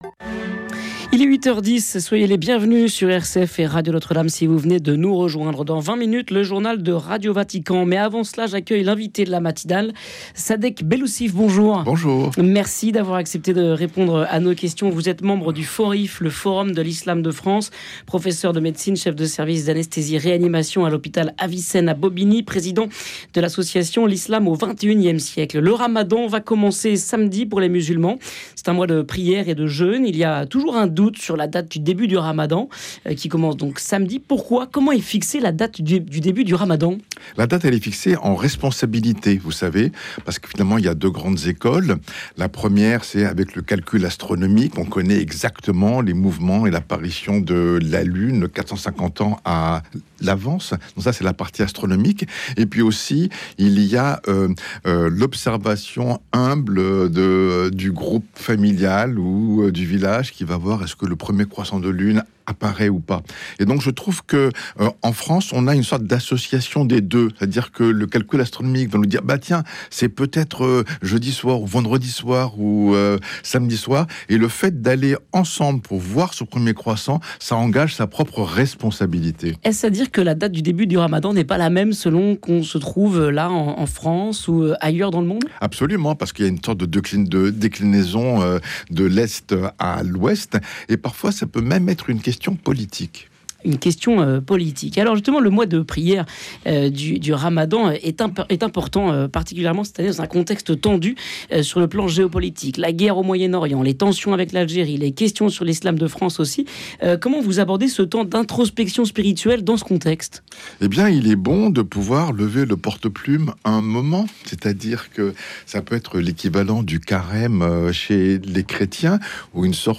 L'invité de la matinale